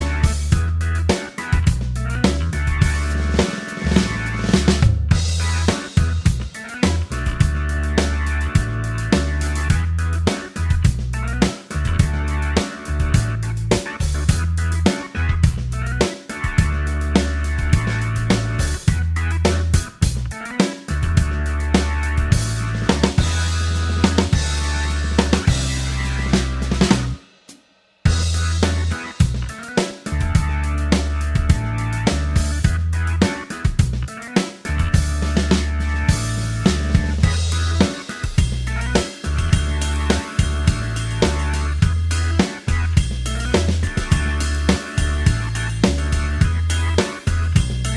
Minus All Guitars Soft Rock 3:46 Buy £1.50